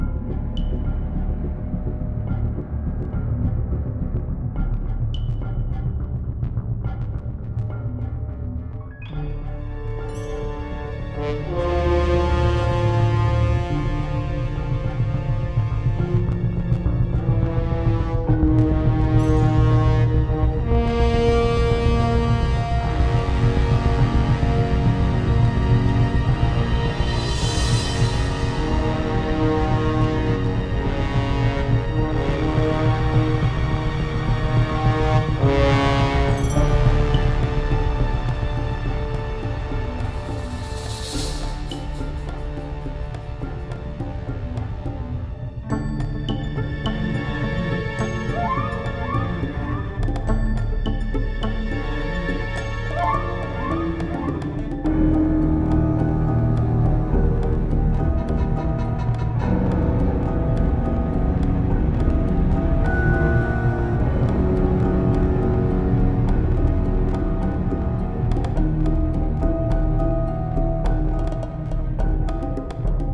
Ambient (loop):
GameMusicWorkshop_Uncharted-Ambient_04_sum.mp3